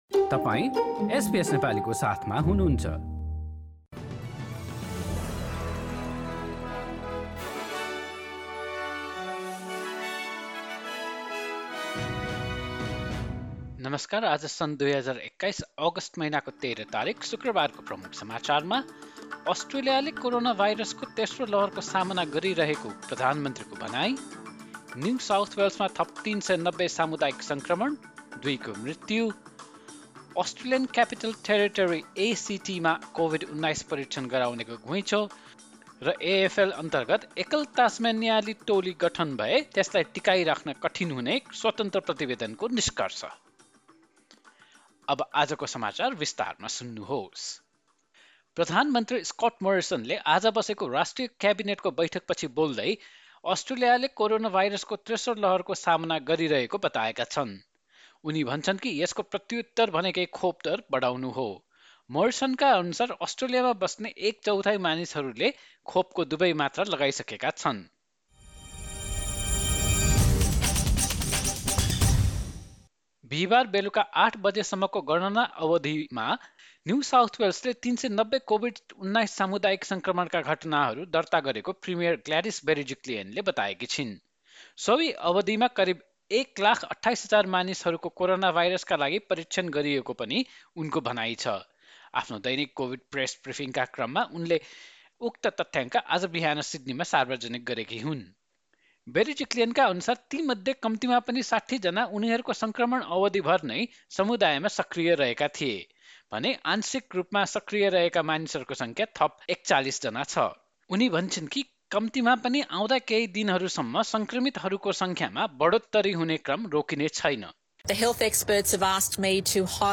एसबीएस नेपाली अस्ट्रेलिया समाचार: शुक्रवार १३ अगस्ट २०२१